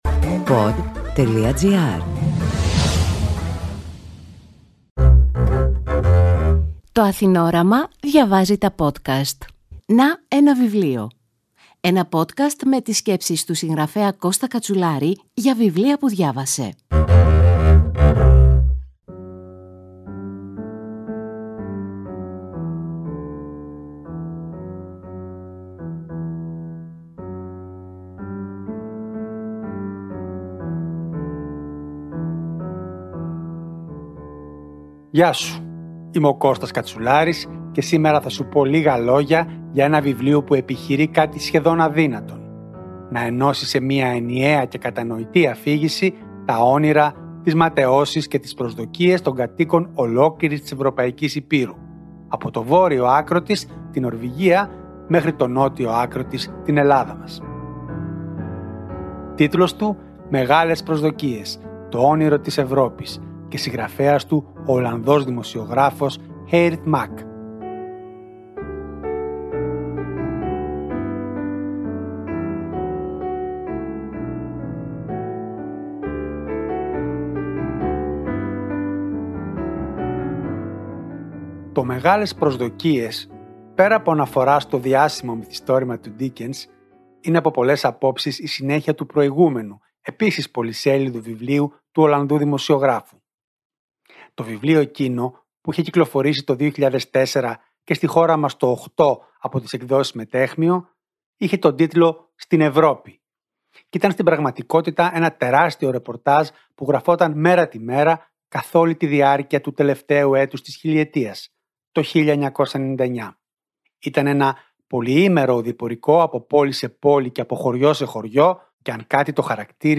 Πόσο ζωντανό είναι σήμερα το όνειρο μιας ενωμένης Ευρώπης; Ένα πανόραμα των τελευταίων τριάντα χρόνων, μέσα από μαρτυρίες εκατοντάδων ανθρώπων. Αποσπάσματα διαβάζει ο Στέλιος Μάινας.